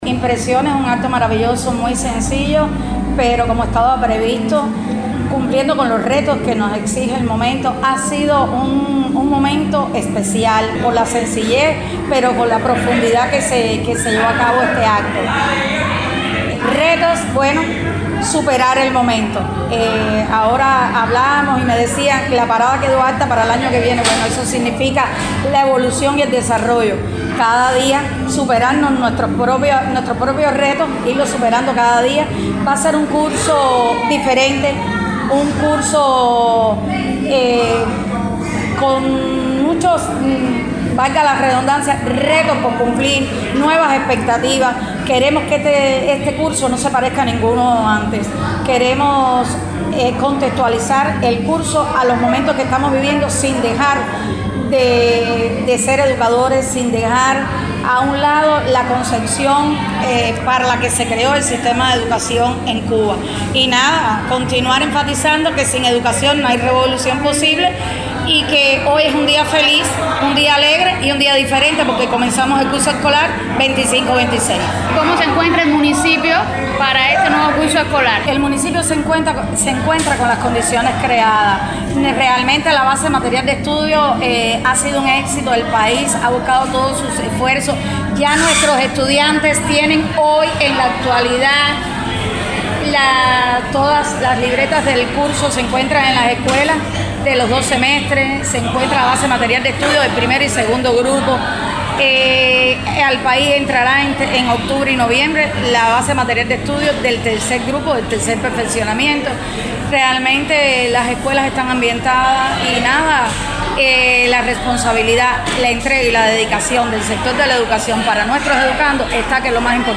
Con una emotiva gala político-cultural, alumnos y profesores se reencontraron este lunes, para dar inicio al curso escolar 2025-2026.